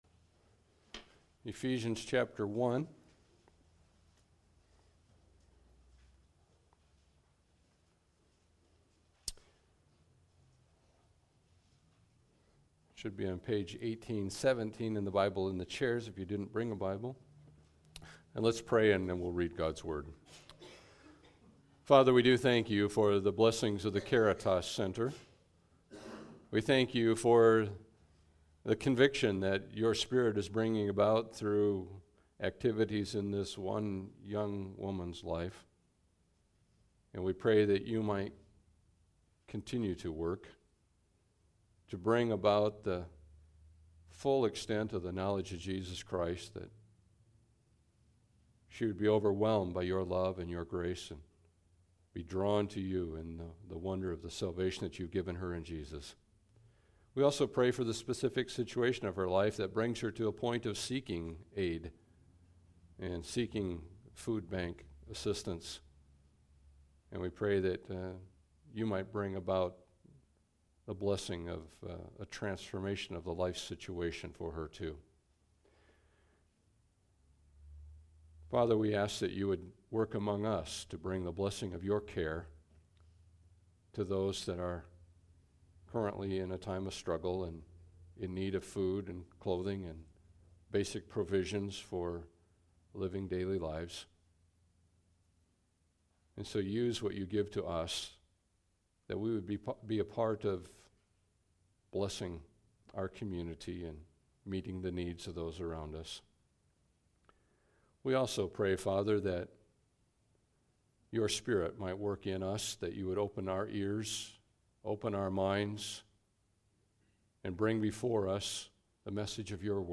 Passage: Ephesians 1 Service Type: Sunday Service